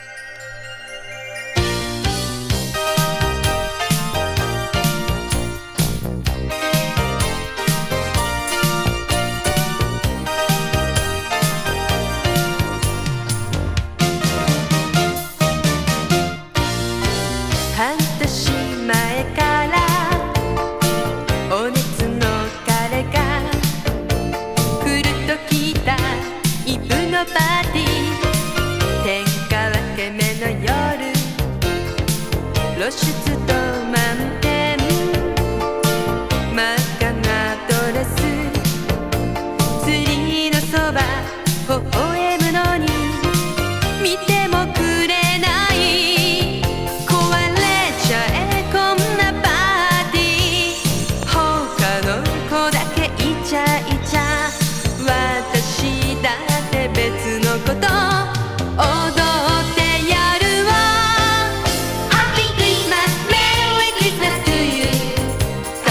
ちびっ子のコーラスがキュートな、シンセポップ・クリスマスソング